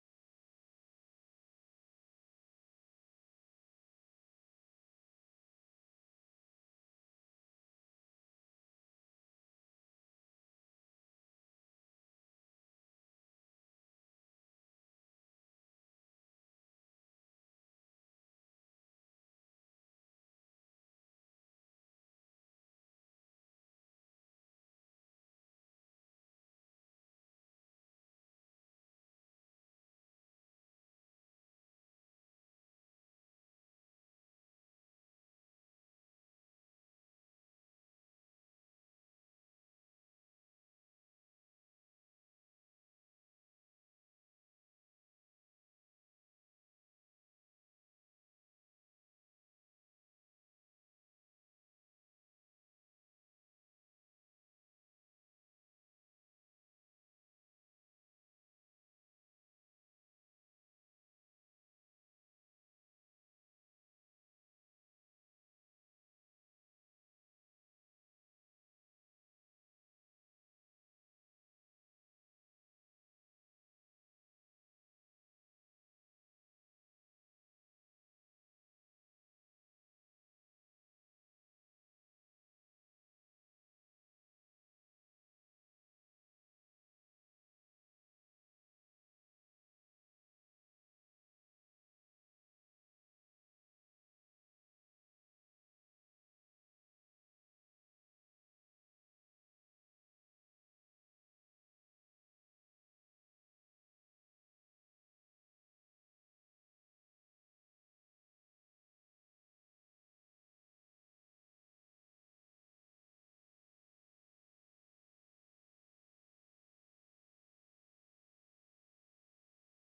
SFX_Scene06_Door.ogg